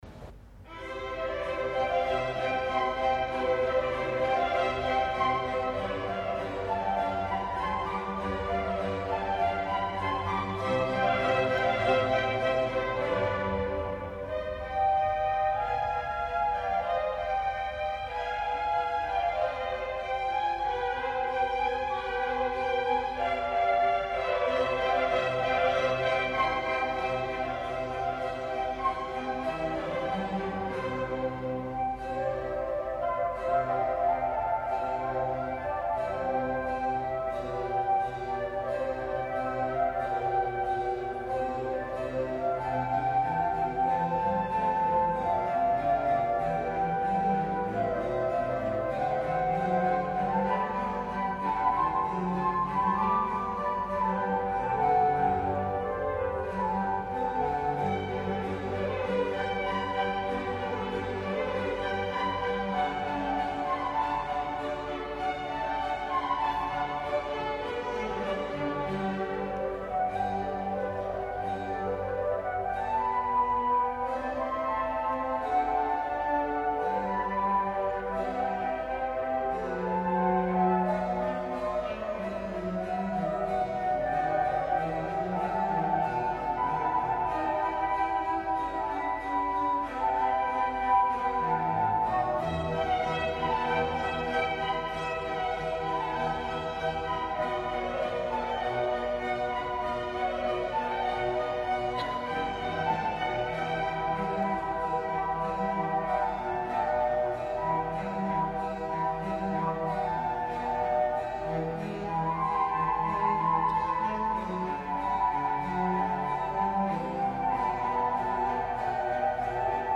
Il ne reste pas grand chose non plus pour témoigner de cette époque, mais j'ai retrouvé un vieil enregistrement d'un concerto pour 2 flûtes de Vivaldi, donné en concert dans une église de Brest avec l'orchestre universitaire.
Je joue la seconde flûte